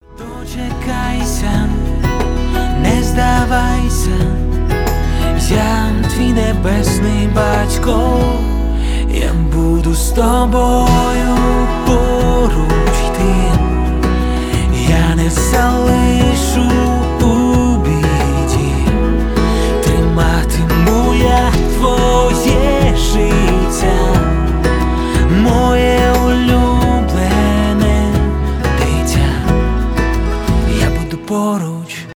христианские
поп